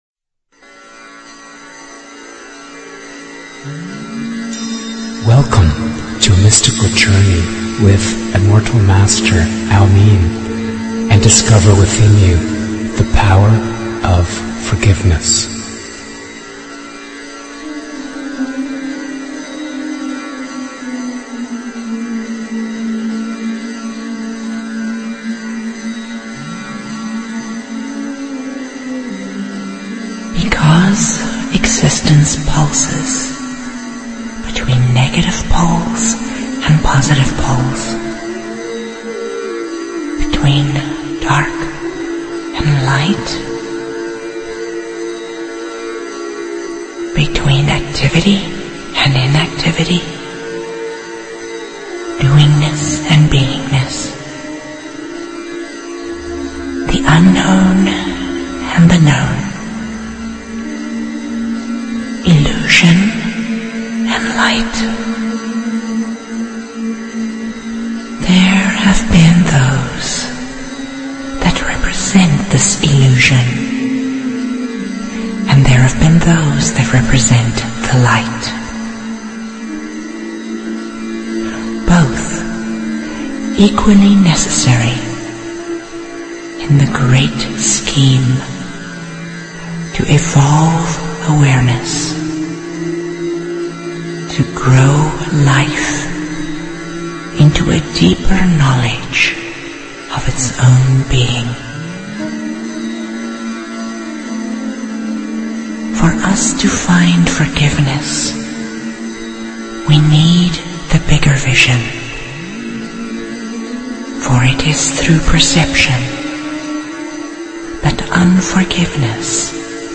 These interviews are powerful enough to change your life!